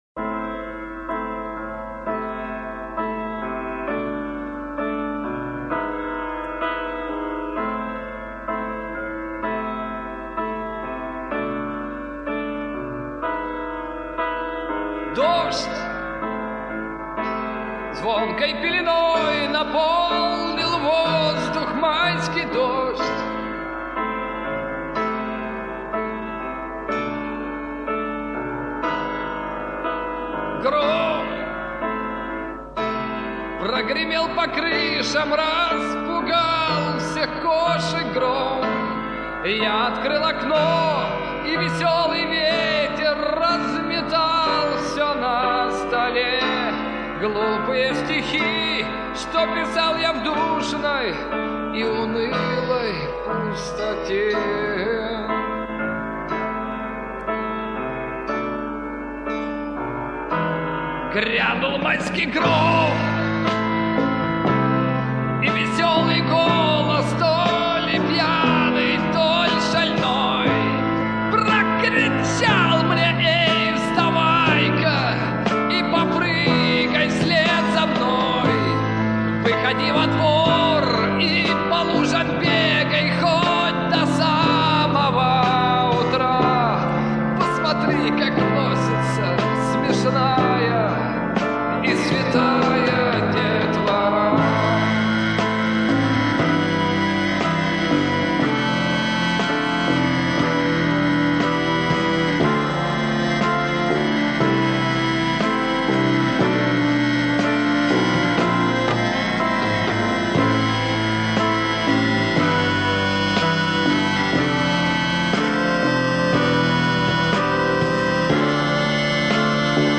Жанр: Рок
Был записан на магнитных носителях и содержал восемь песен.